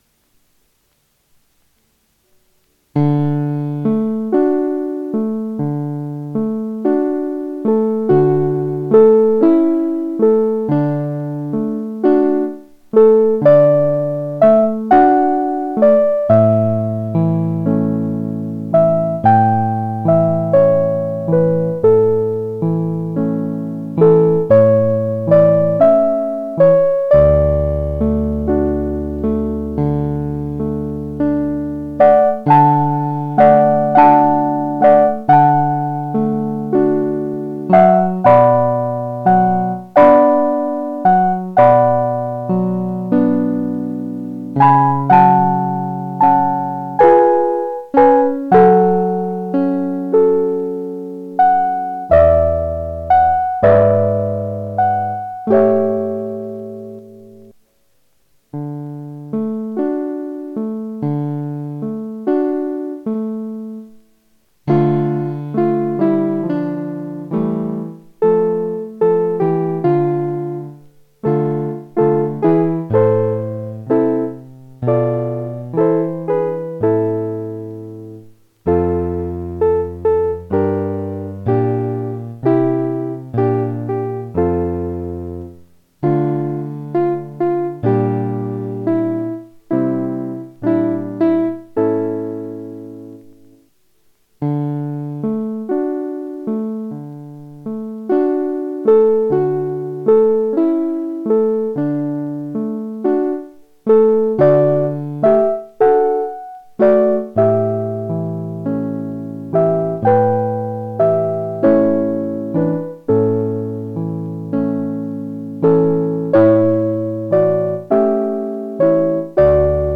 Piezas para piano: 37.- Barcarola (
barcarola.mp3